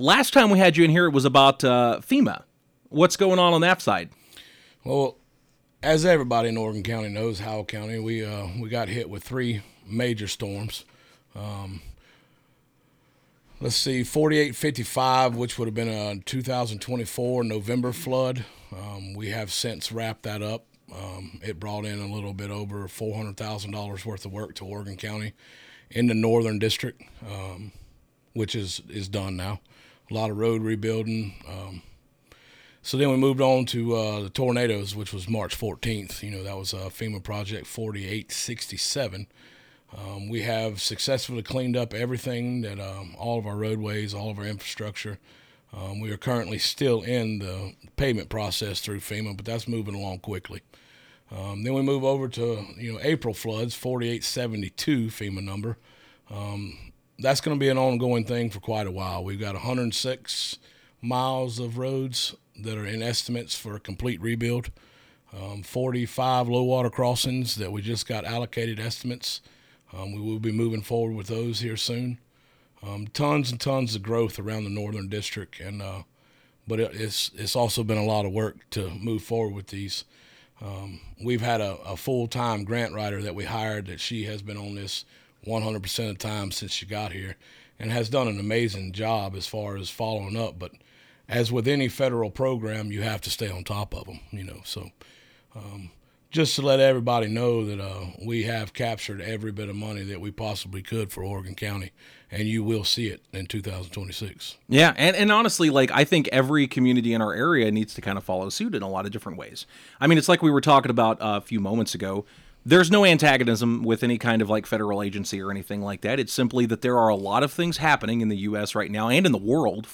Oregon County Commissioner Jake Parker in the studio of KUKU on February 2nd, 2026.